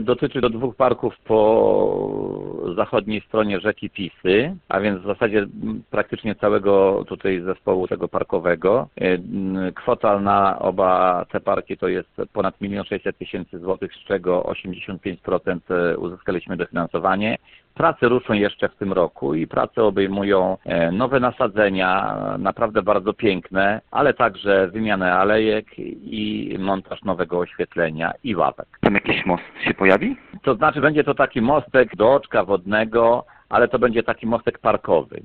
Jak mówi włodarz miasta, dwa parki znajdujące się na zachodnim brzegu rzeki Pisy zmienią się nie do poznania.